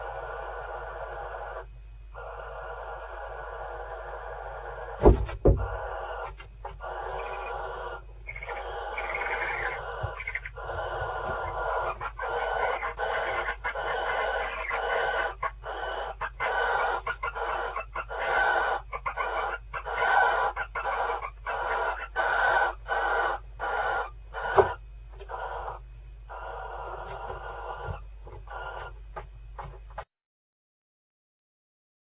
chouettes effraie
accouplement
(filmé en infra rouge avec son)